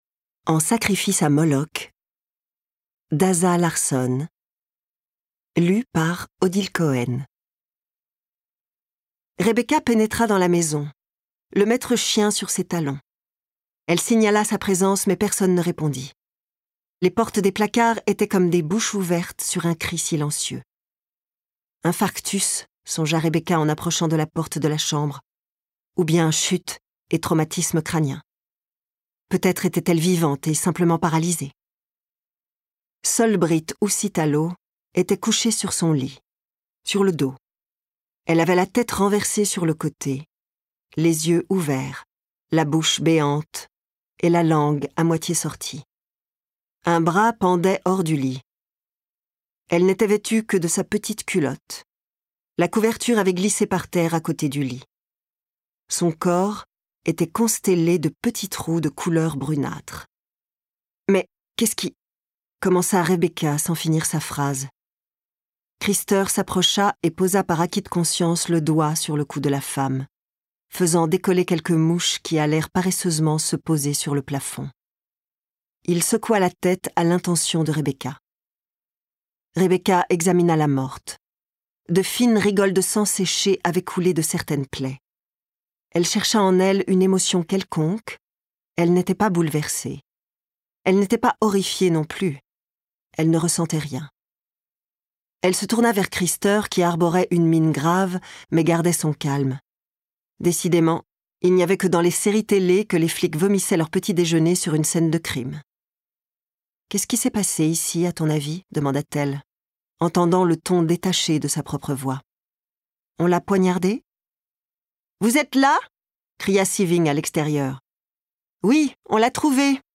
Elle a su également donner corps aux deux époques différentes, en modulant sa voix quand il s’agissait de la partie historique, celle de l’institutrice. Elle lui a donné un petit côté innocente et ingénue qui lui correspond tout à fait.